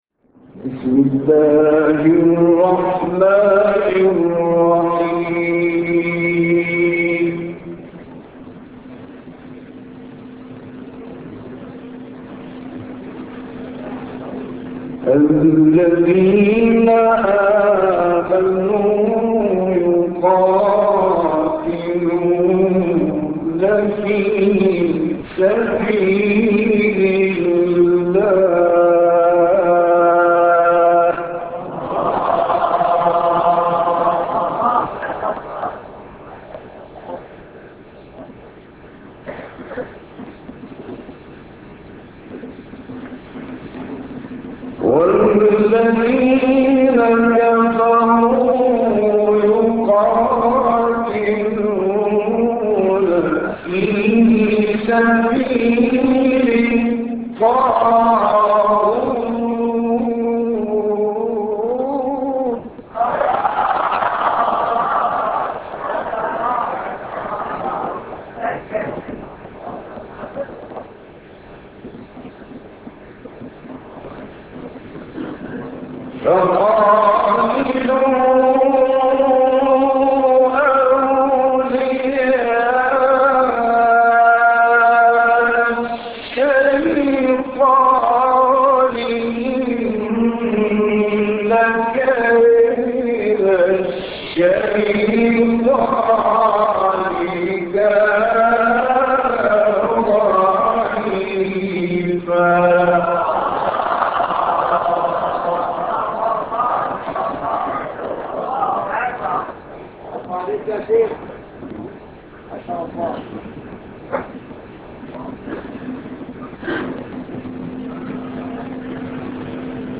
تلاوت آیاتی از سوره نساء توسط استاد راغب مصطفی غلوش